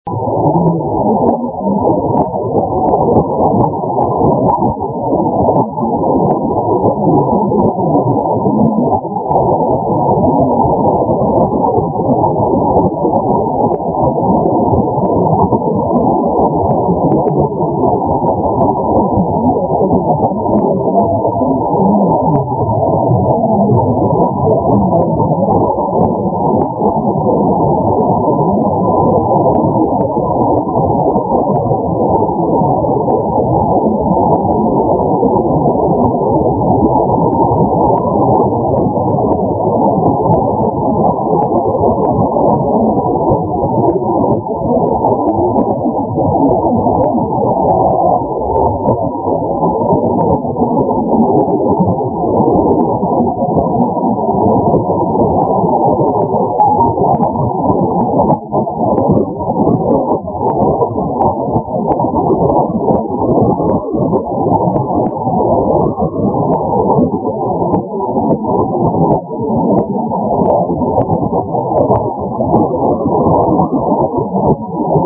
すべて名古屋市内の自宅での音なので人工ノイズ付です。（HI!)
現地の朝遅い時間の伝搬の音
この後KZDGの音楽に置き換わってしまいました。